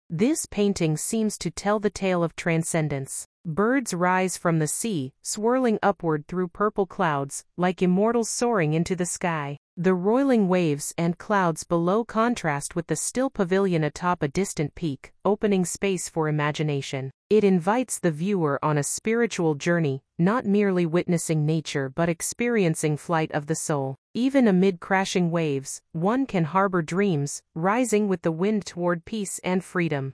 English audio guide